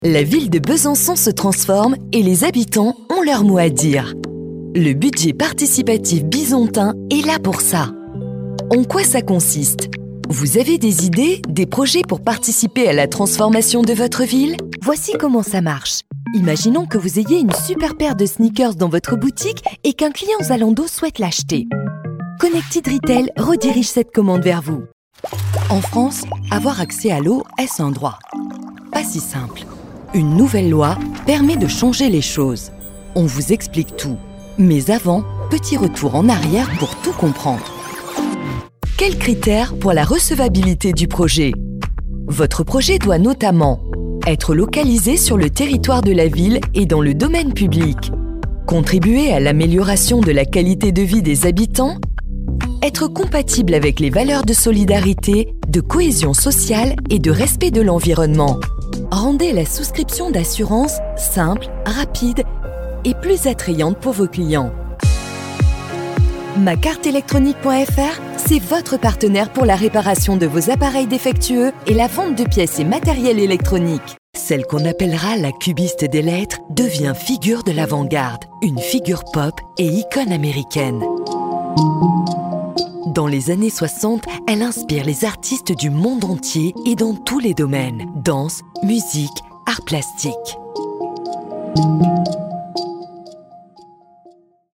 Profonde, Naturelle, Distinctive, Accessible, Polyvalente
Vidéo explicative